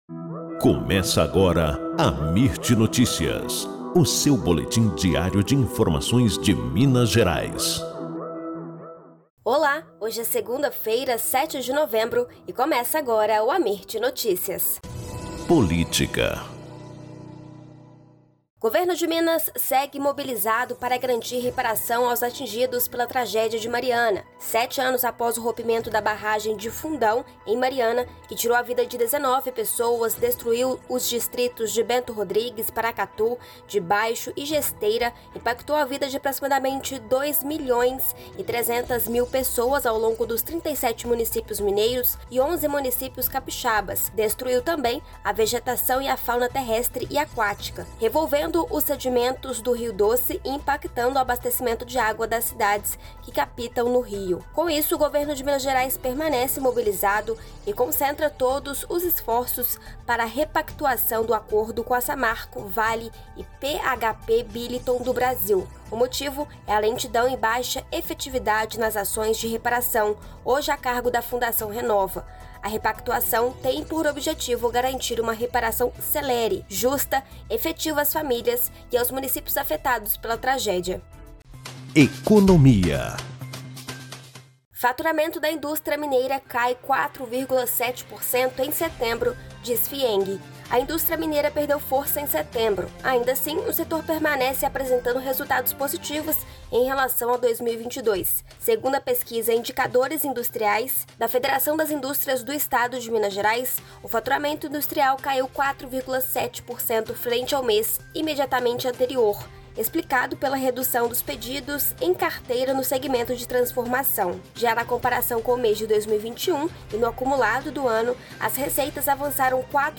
Boletim Amirt Notícias – 07 de novembro